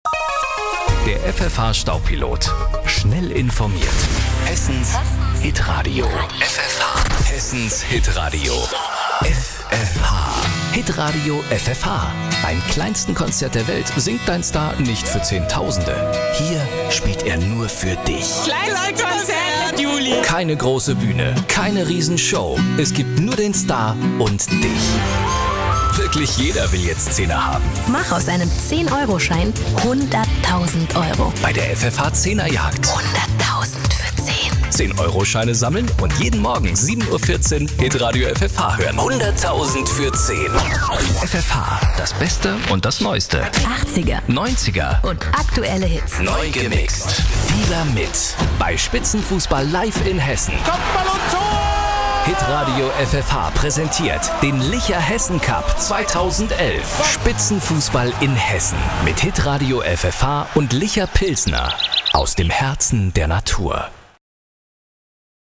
Sehr vielseitige und warme Stimme.
Kein Dialekt
Sprechprobe: Industrie (Muttersprache):
german voice over artist